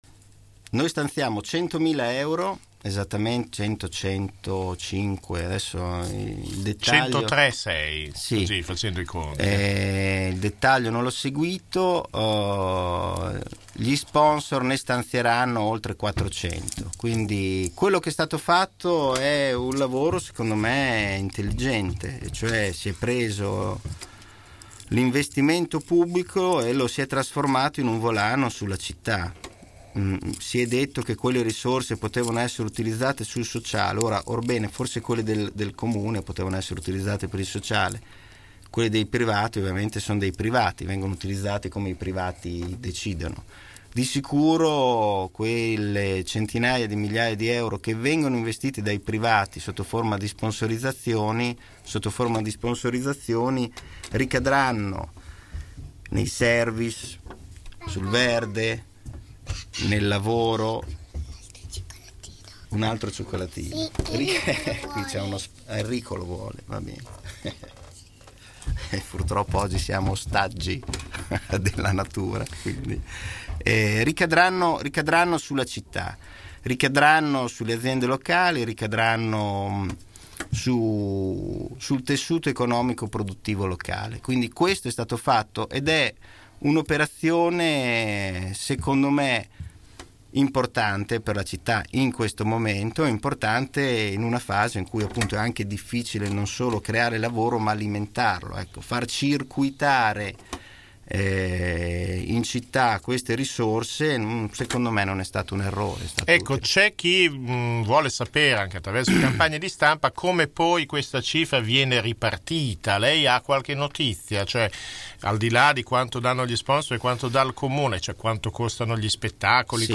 Microfono aperto stamattina in radio con il  Vicesindaco Claudio Merighi rimasto vittima, come altre migliaia di bolognesi, del traffico in tilt.